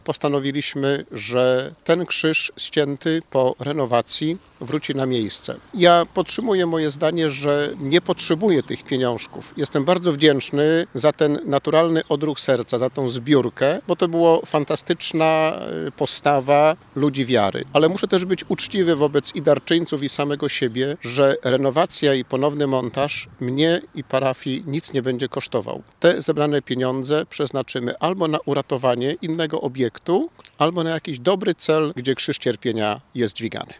Dziś na ten temat rozmawiali goście programu RZG interwencje.